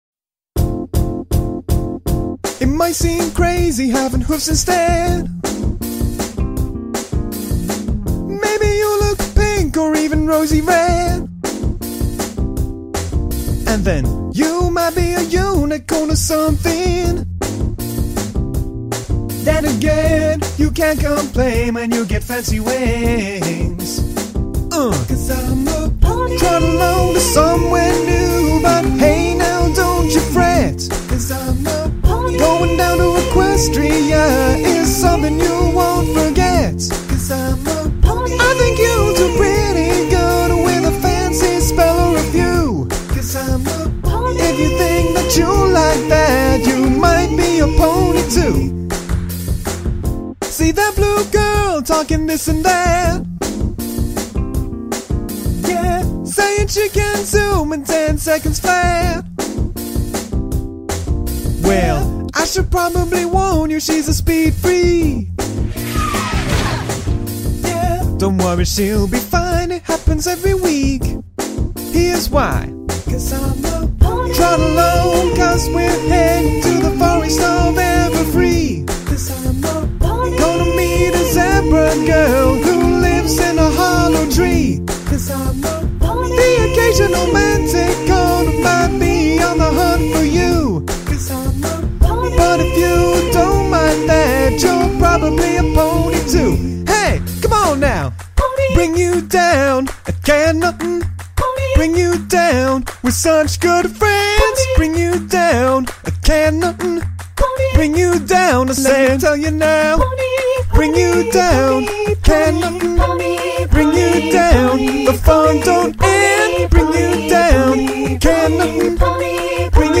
A silly little parody for you to enjoy!